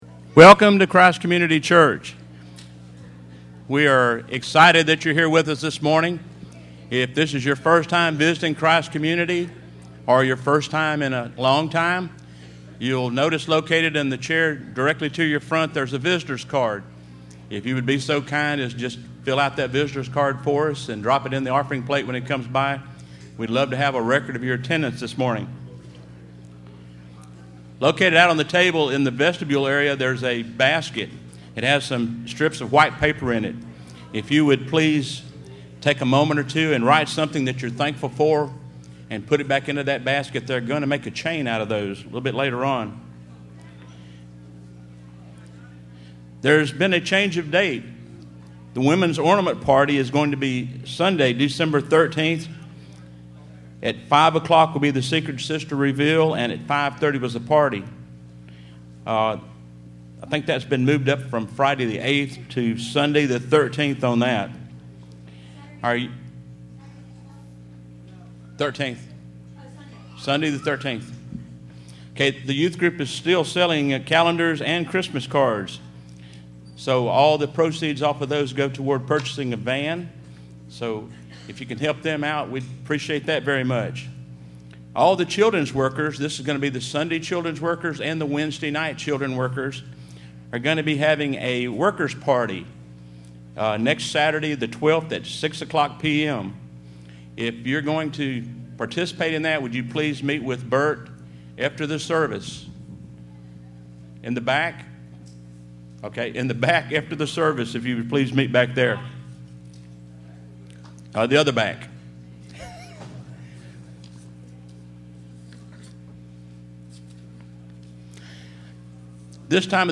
Listen to The Promise of a Bright Future - 12_06_15_Service.mp3